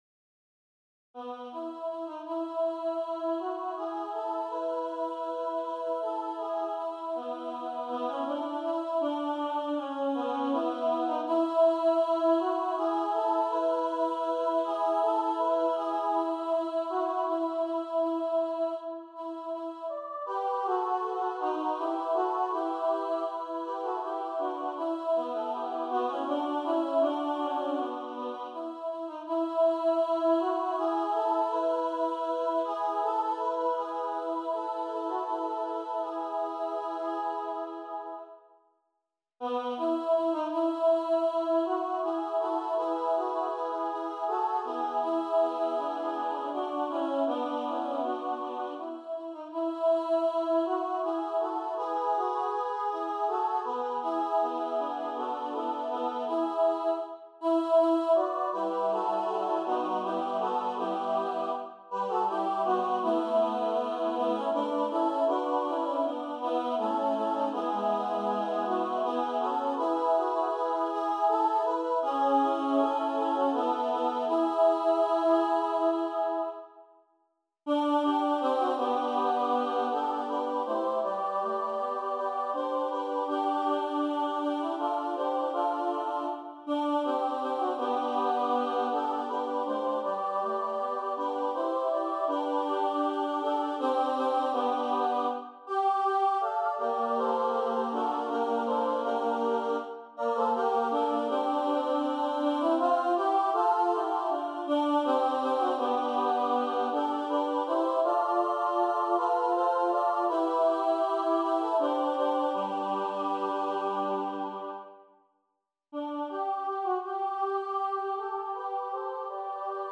tradisjonell irsk Christmas Carol